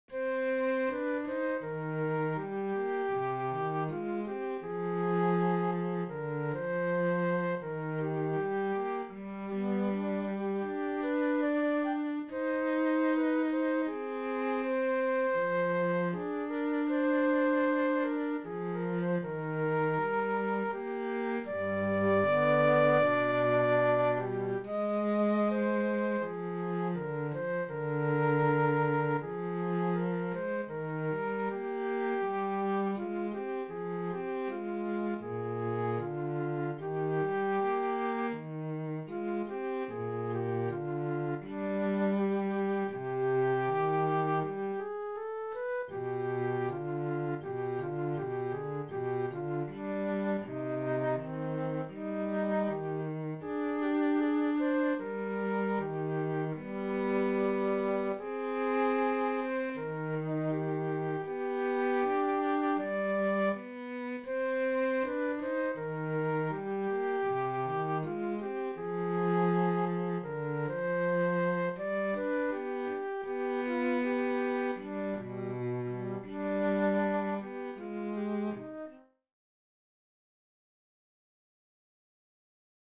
Syva-ja-kirkas-huilu-ja-sello.mp3